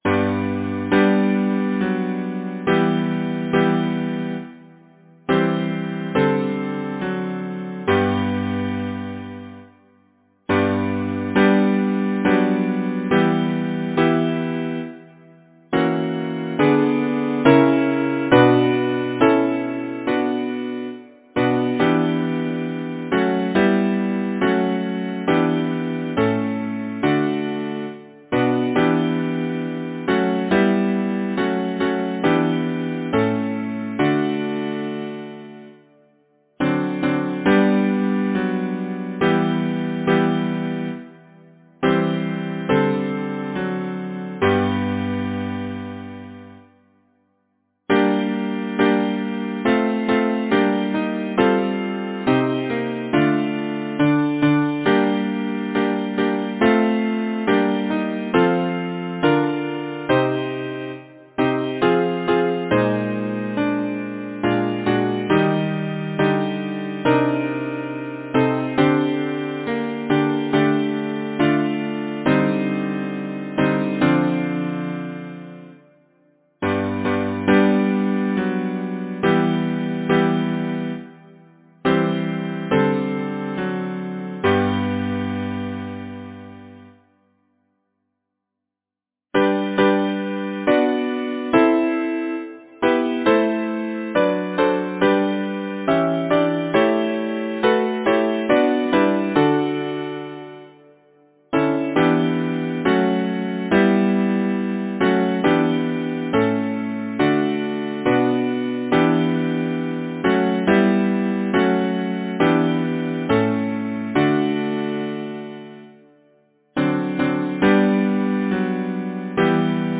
Title: The Tide rises, the Tide falls Composer: Adam Carse Lyricist: Henry Wadsworth Longfellow Number of voices: 4vv Voicing: SATB Genre: Secular, Partsong
Language: English Instruments: A cappella